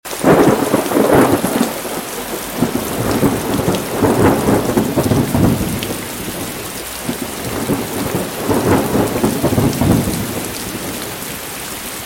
Heavy Rain, night ambience and sound effects free download